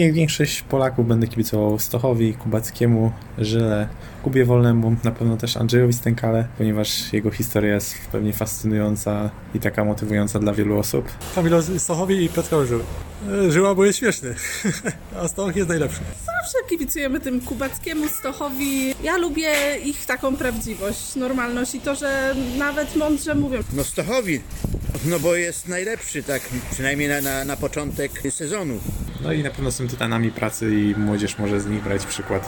Zapytaliśmy zielonogórzan komu będą kibicować w tegorocznym sezonie oraz za co sobie cenią polskich skoczków: